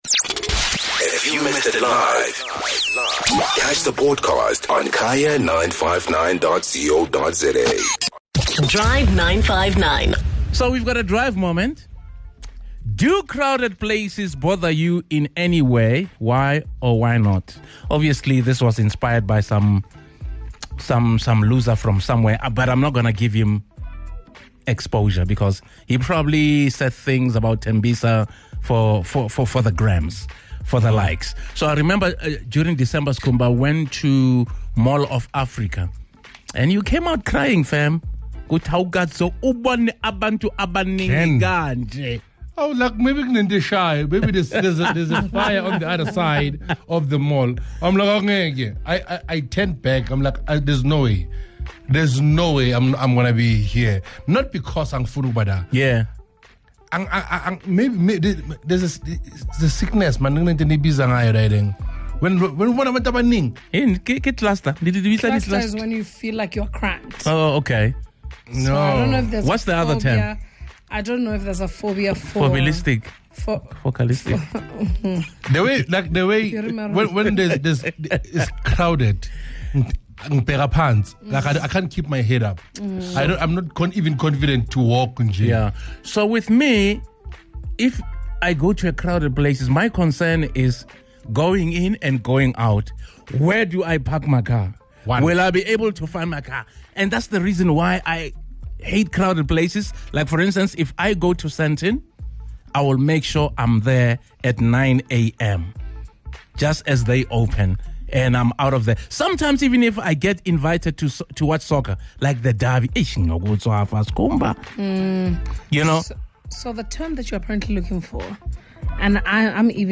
The Drive 959 team and listeners share their interesting take on the extent to which they can and can't handle the bustle of a big crowd.